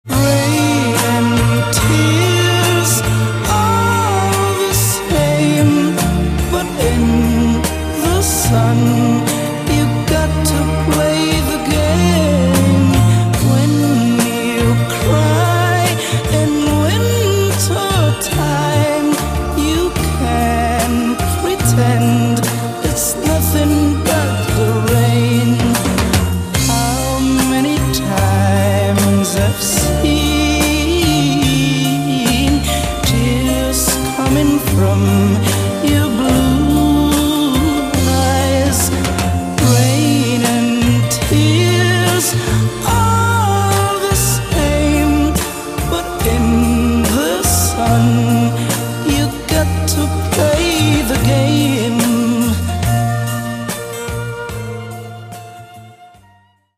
Senza bassi, un vibrato stretto stretto...